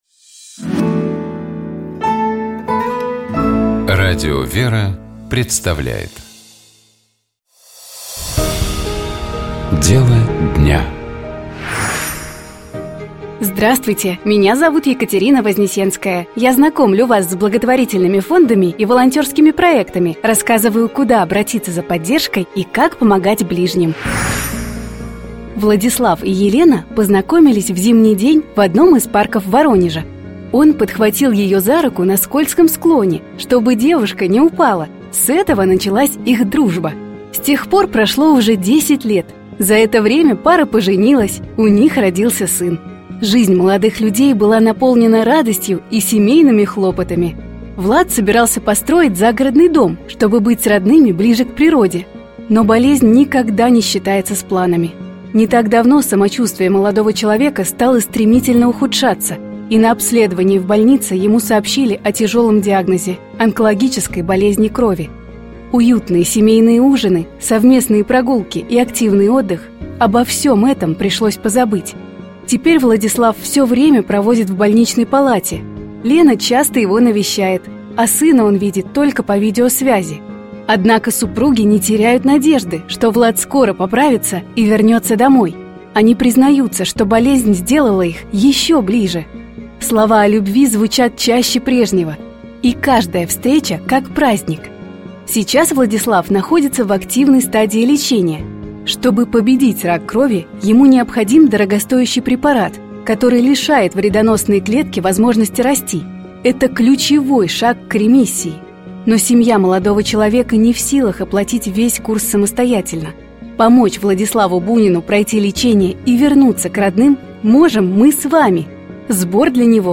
Комментирует священник